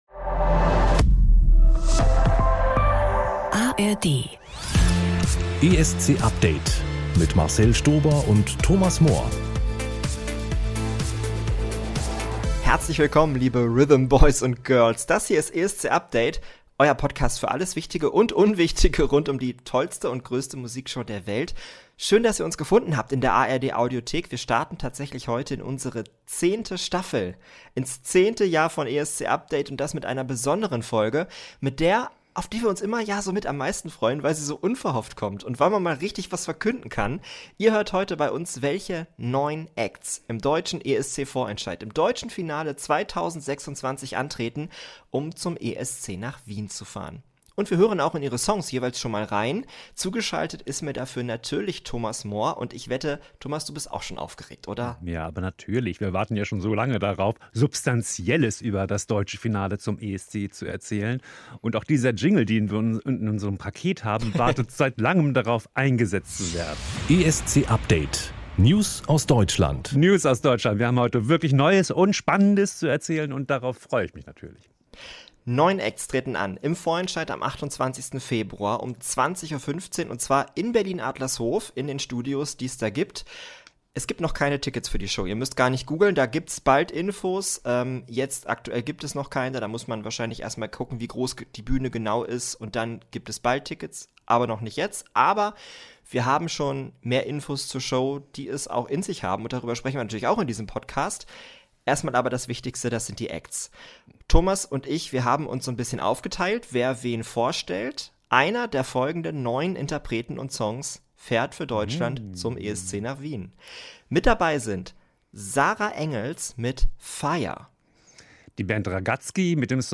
ESC Update Author: NDR Blue Language: de Genres: Music , Music Commentary Contact email: Get it Feed URL: Get it iTunes ID: Get it Get all podcast data Listen Now...